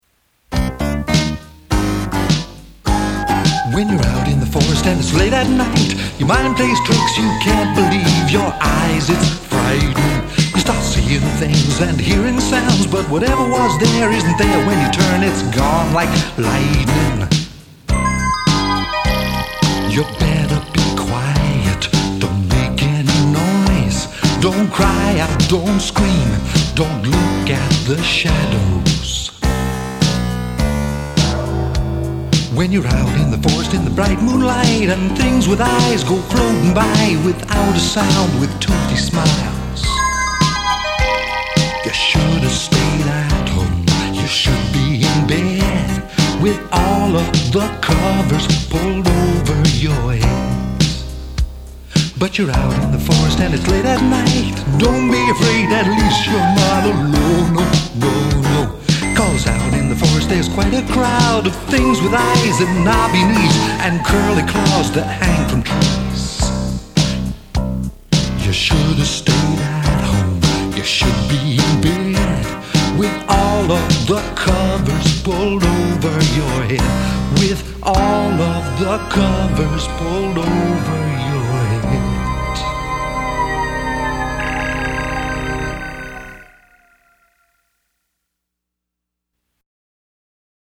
This particular song, as I was about to say before I sidetracked myself, was recorded at Millstream Studios in Cheltenham, England. It was not intended as a Halloween song, but when one of my granddaughters heard it she said “That’s a Halloween song, Pops!” (She’s a very perspicacious child).